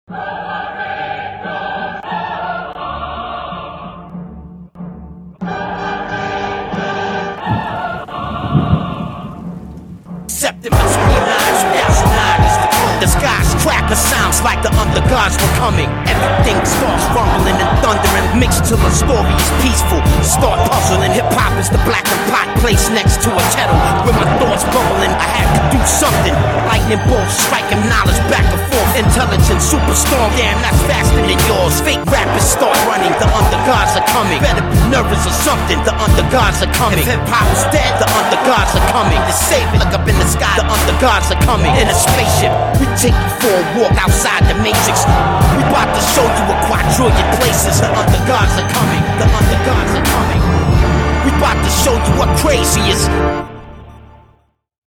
freestyle